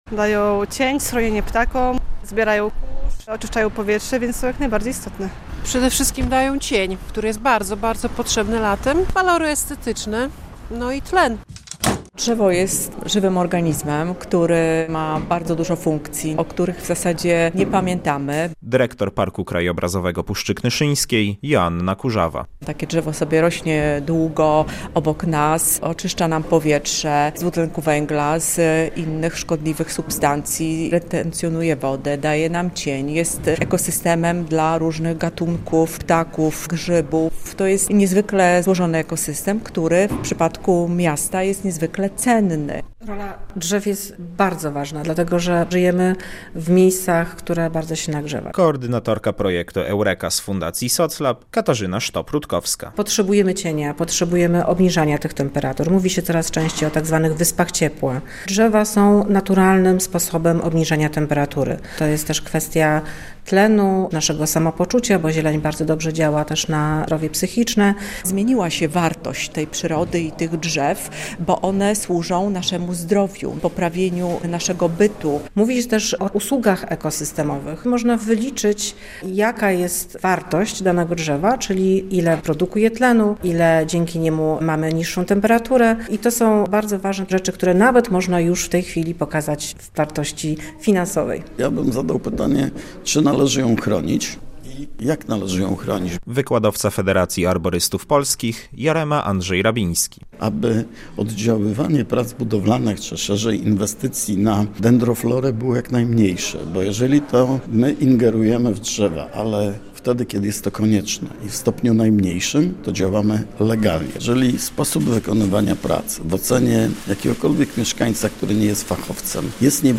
Drzewa w mieście, seminarium - relacja